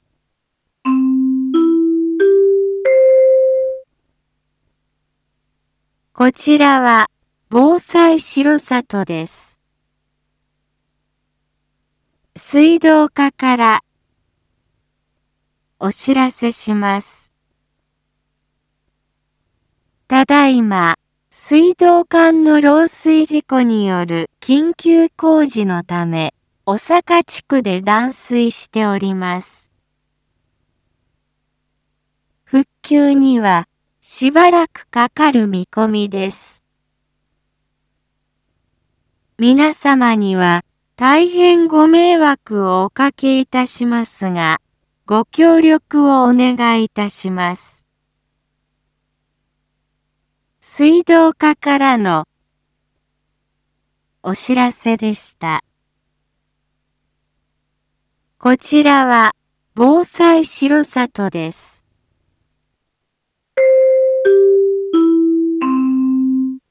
Back Home 一般放送情報 音声放送 再生 一般放送情報 登録日時：2024-01-09 11:04:08 タイトル：R6.1.9小坂断水 インフォメーション：こちらは、防災しろさとです。